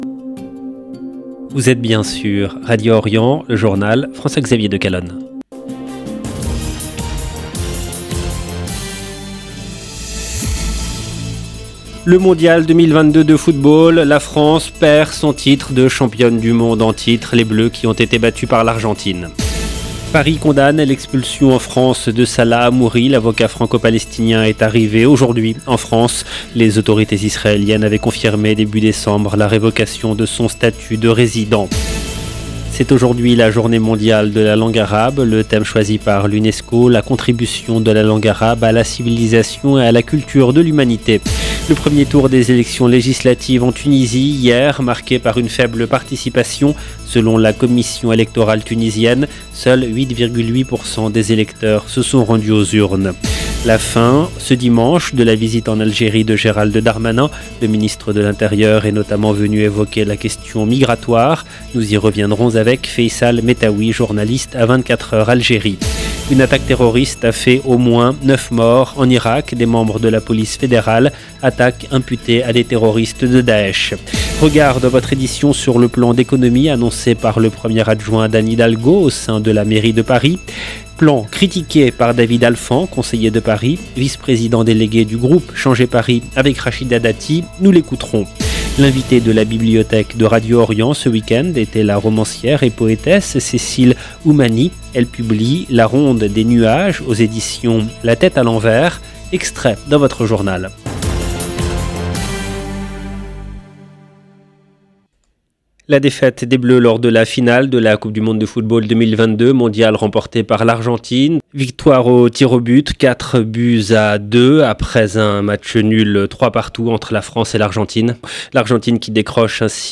EDITION DU JOURNAL DU SOIR EN LANGUE FRANCAISE DU 18/12/2022